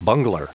Prononciation du mot bungler en anglais (fichier audio)
bungler.wav